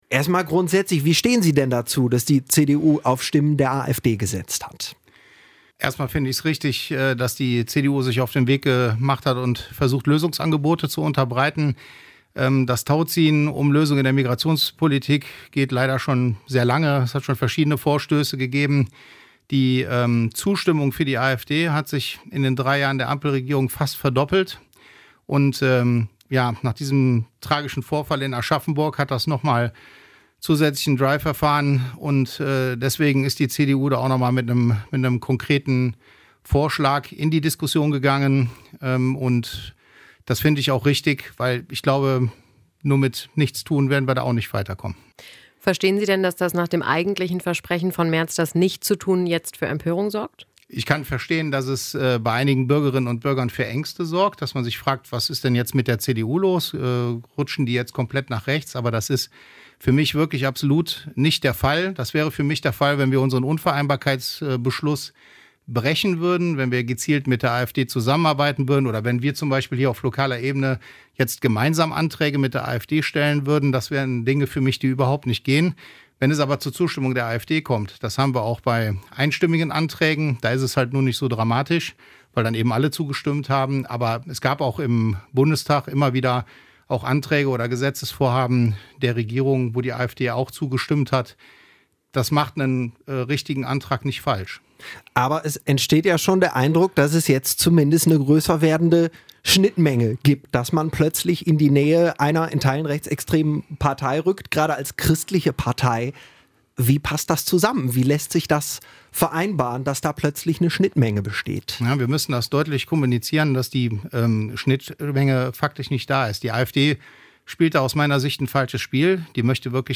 im Gespräch mit Radio Leverkusen